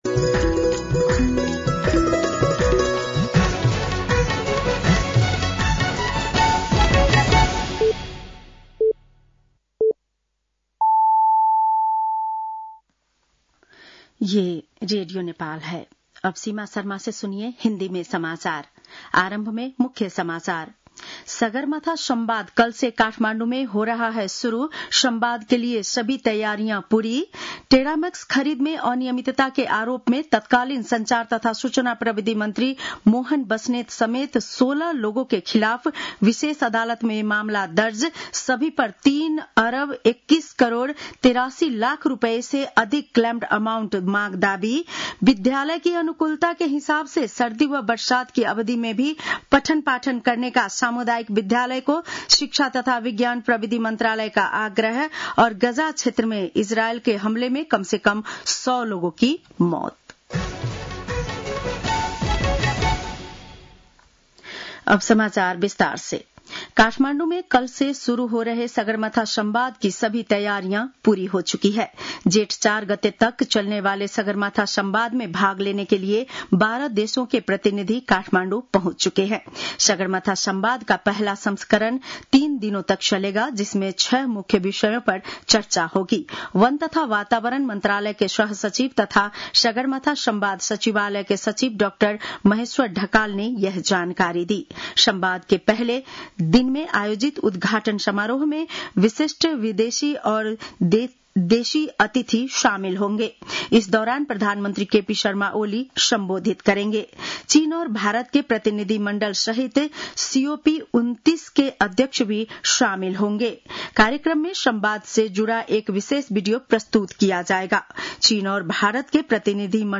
बेलुकी १० बजेको हिन्दी समाचार : १ जेठ , २०८२